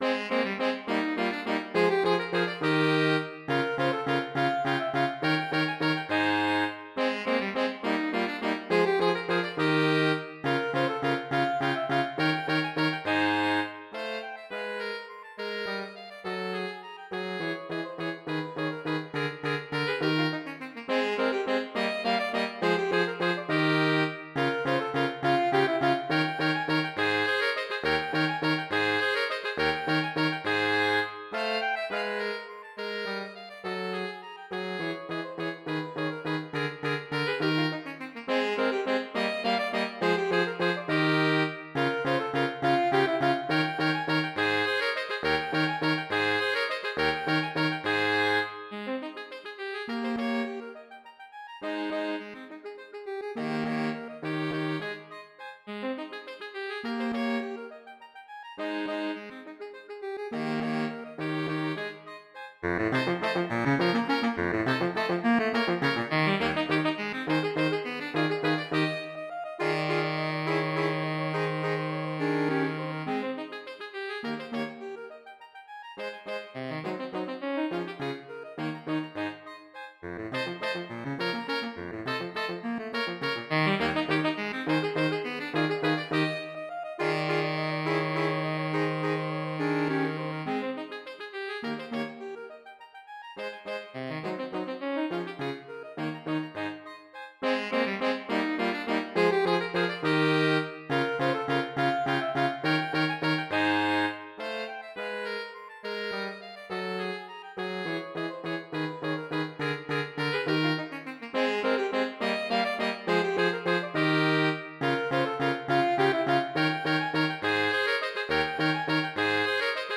4 SATB. Fast Minuet, not composed for dancing.